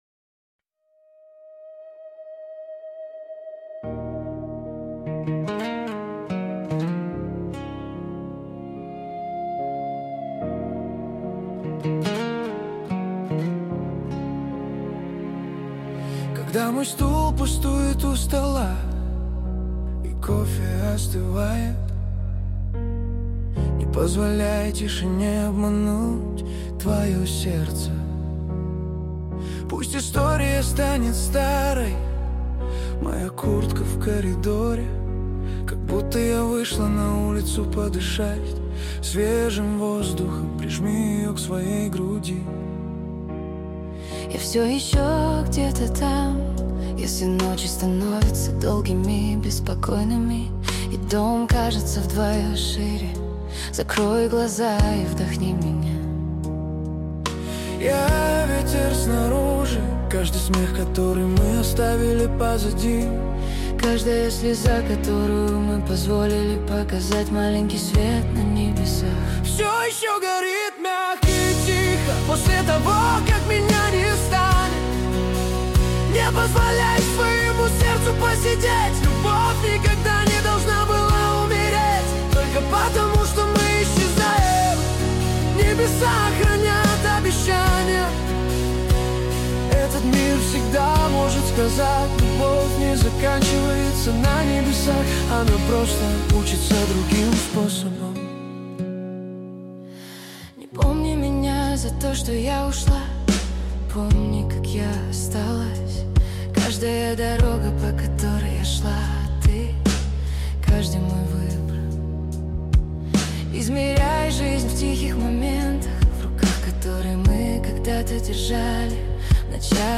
Качество: 320 kbps, stereo
Русские треки, Поп музыка